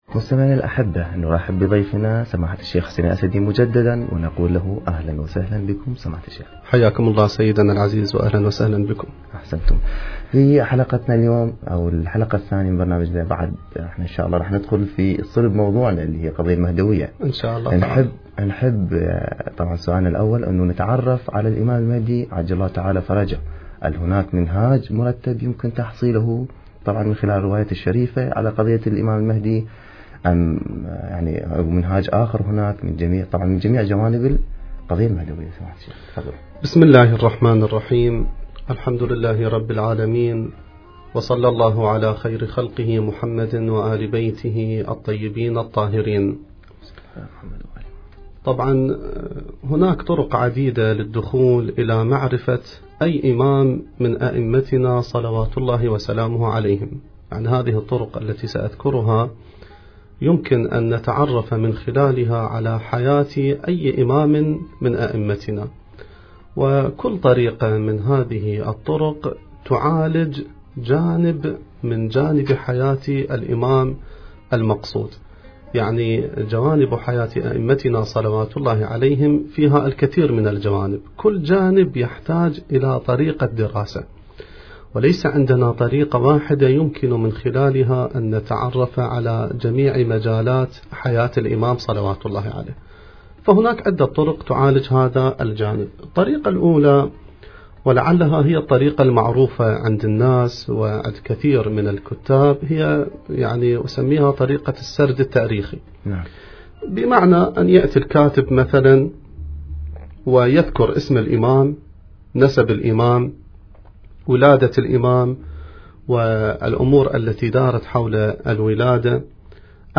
المكان: اذاعة الفرات